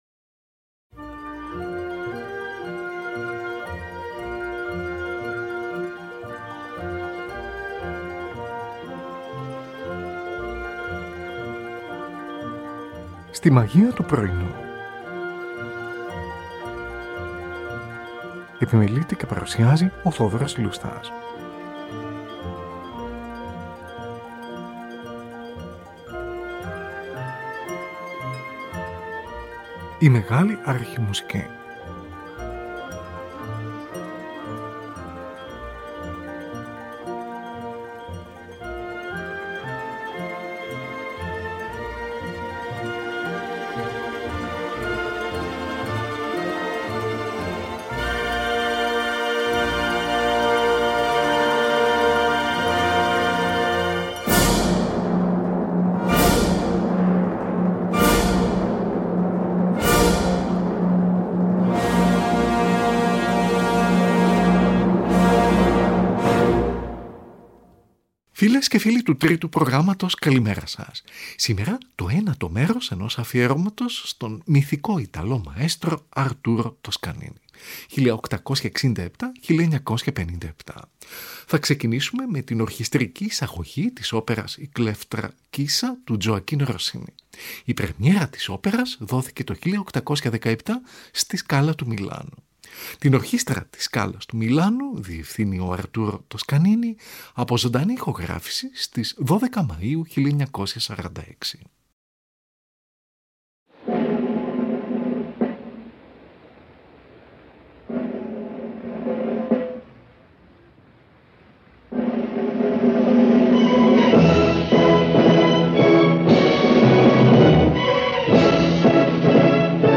Ορχηστρική Εισαγωγή
από ζωντανή ηχογράφηση στις 12 Μαΐου 1946.
mezzo-soprano
στην εκδοχή για ορχήστρα